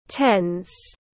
Tense /tens/
Bạn cũng có thể đọc theo phiên âm của từ tense /tens/ kết hợp với nghe phát âm sẽ đọc chuẩn hơn.